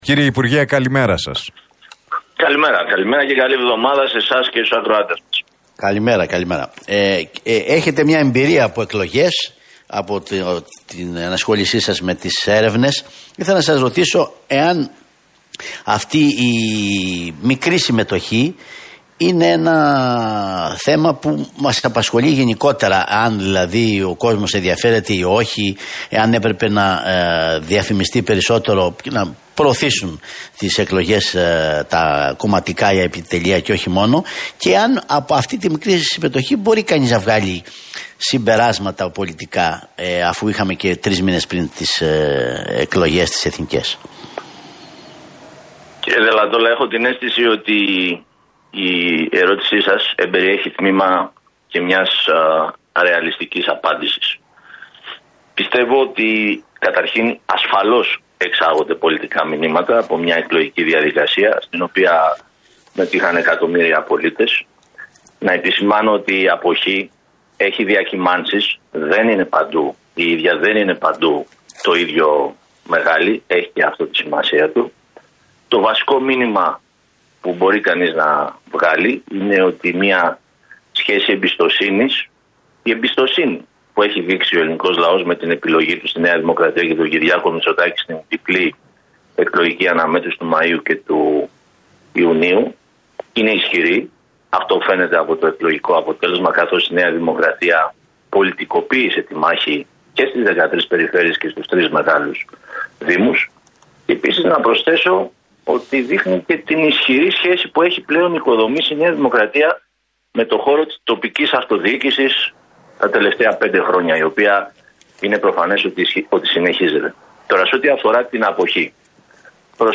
Θεοδωρικάκος στον Realfm 97,8: Οφείλουμε όλοι να είμαστε προσγειωμένοι – Ο ΣΥΡΙΖΑ βρίσκεται σε περιδίνηση